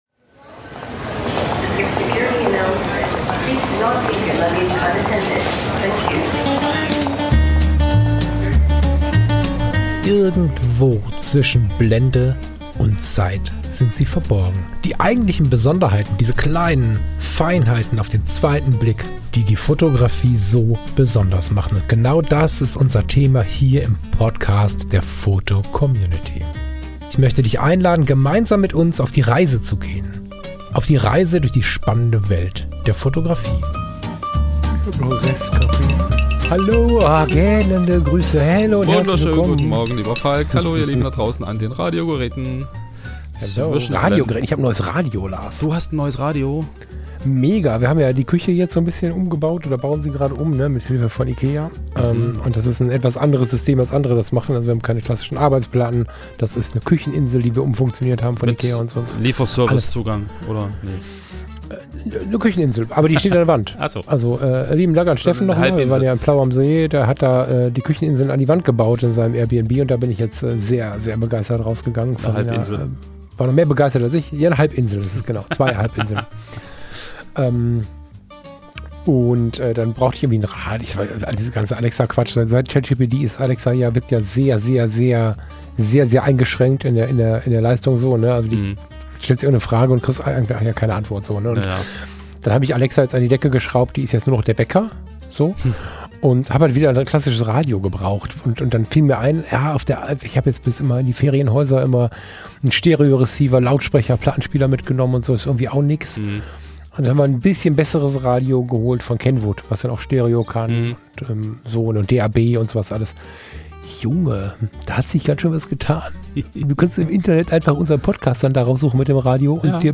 fotografisches Sonntagsgespräch